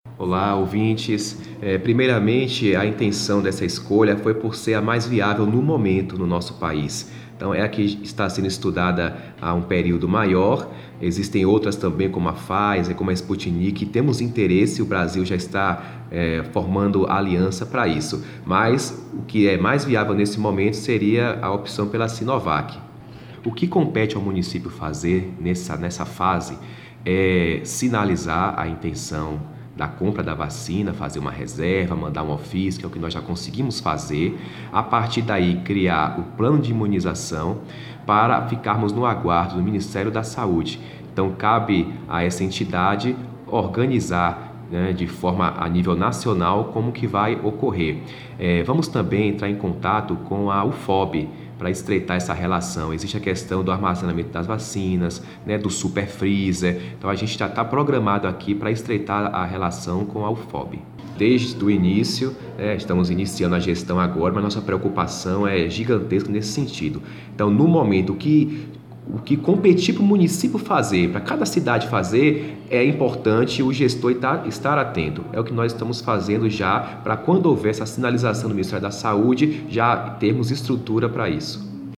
Acesse nosso podcast e ouça o secretário de Saúde Darkison Marques